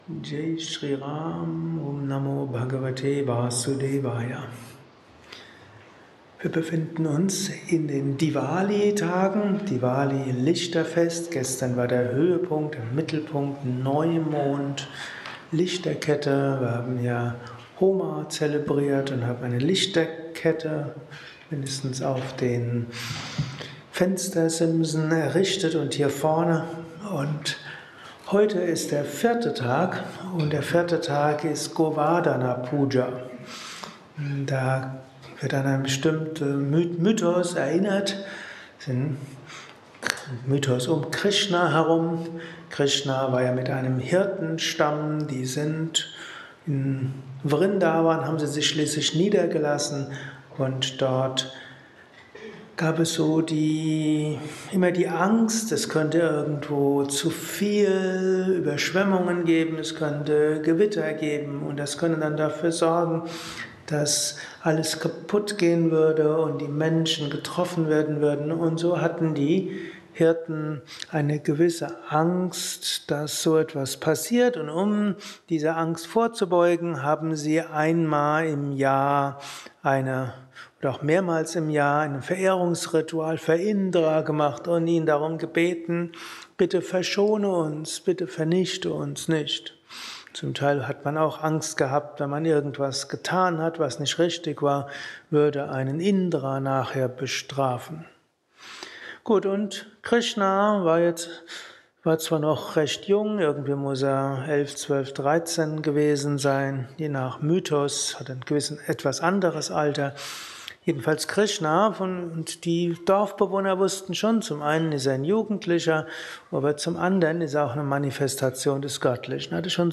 Kurzvorträge
kurzer Vortrag als Inspiration für den heutigen Tag von und mit
eines Satsangs gehalten nach einer Meditation im Yoga Vidya